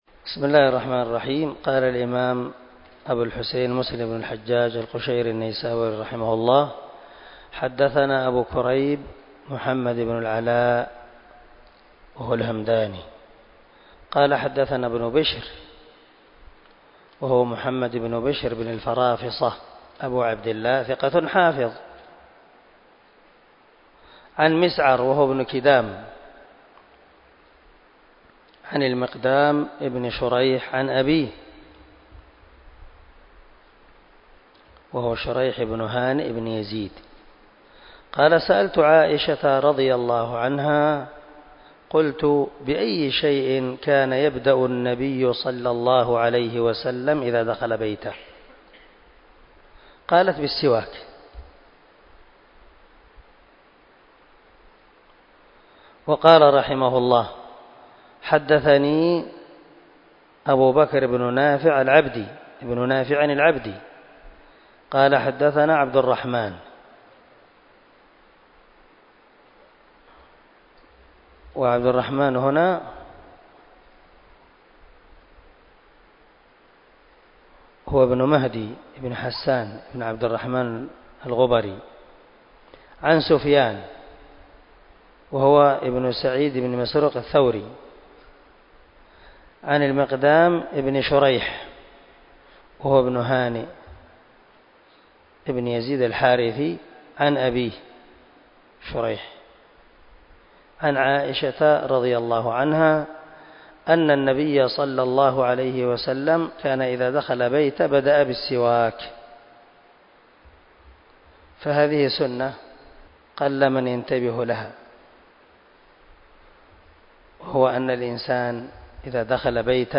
دار الحديث- المَحاوِلة- الصبيحة.